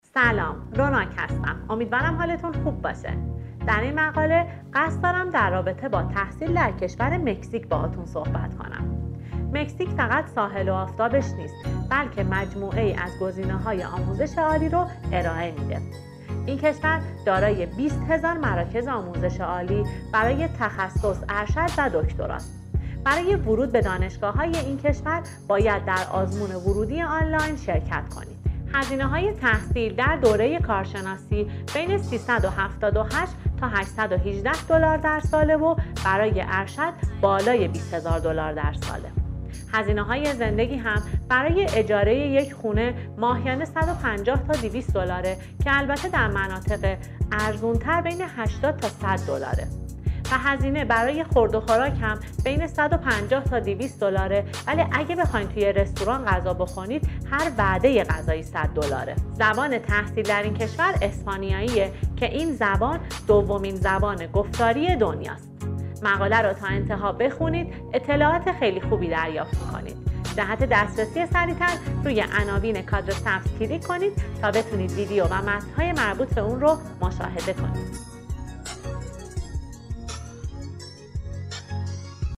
صحبت های همکار ما در این رابطه را شنیده و جهت هرگونه اطلاعات بیشتر با ما در ارتباط باشید.